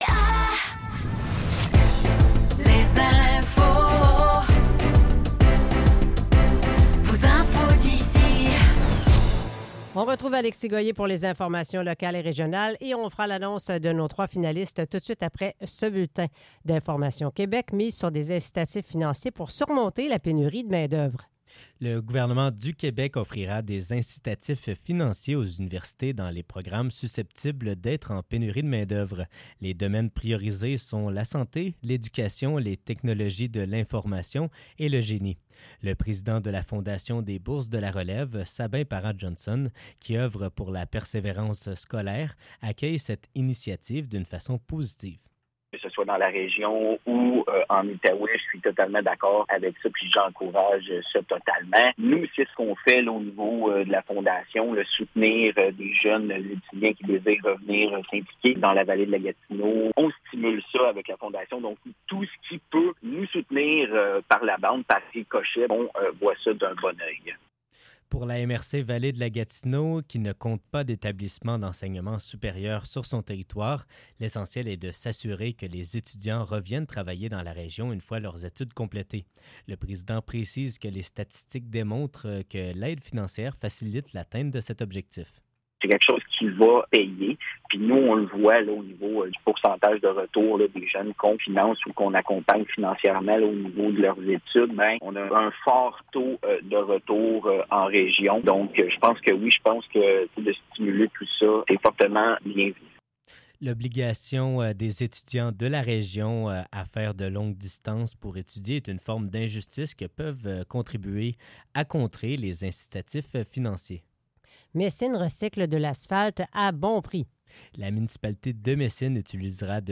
Nouvelles locales - 16 juillet 2024 - 15 h